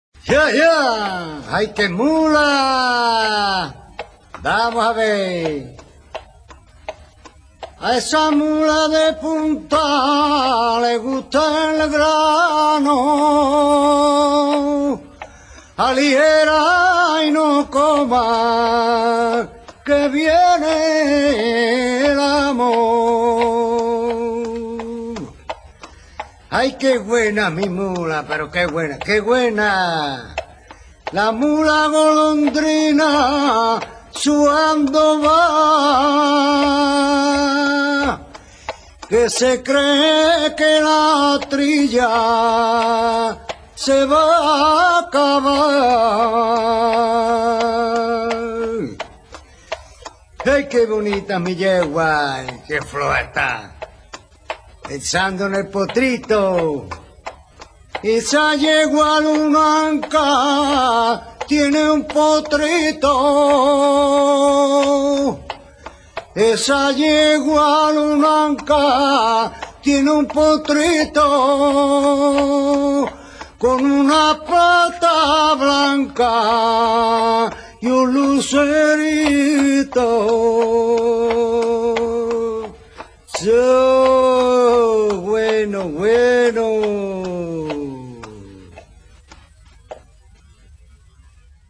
TRILLERA. f (De trillo, este del lat, tribulum.] Cante campero procedente del folklore andaluz, con copla de cuatro versos, el primero y el tercero heptas�labos y el segundo y el cuarto pentas�labos.
El cante de trilla no tiene apoyo de comp�s en la guitarra y su ritmo se sostiene con el solo acompa�amiento de los agudos y claros cascabeles prendidos en los arreos de las caballer�as y las voces arrieras con que se anima y estimula el trabajo de las pobres bestias�.
trillera.mp3